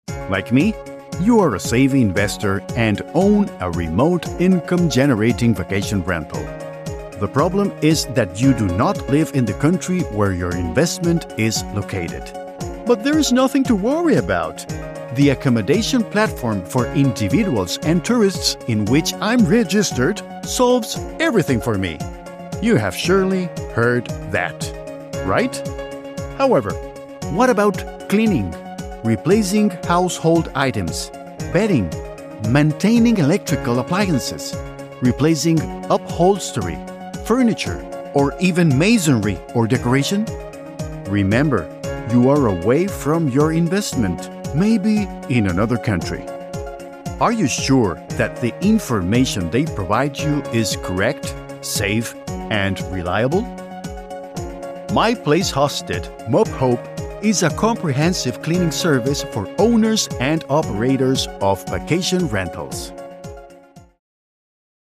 Español (América Latina)
Comercial, Natural, Travieso, Versátil, Empresarial
Corporativo